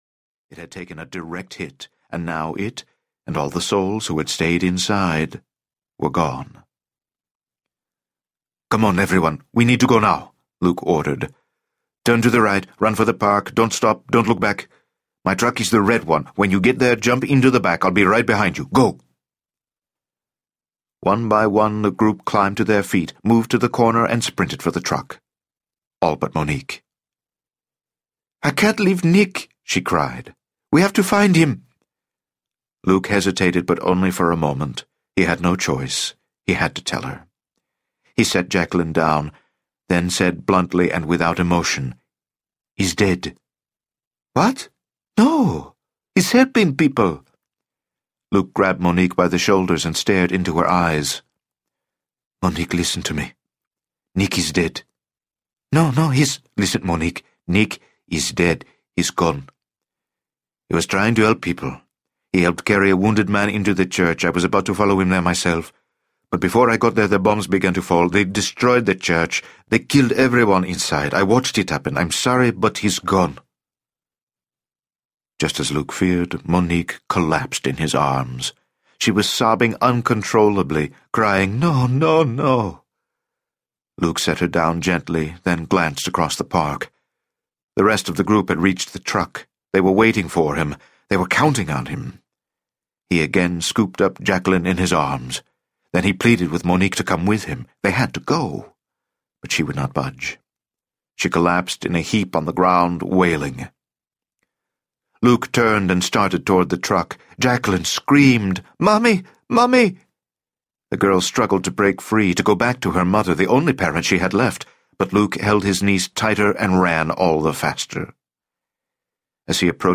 The Auschwitz Escape (Complete) Audiobook
Narrator
14.65 Hrs. – Unabridged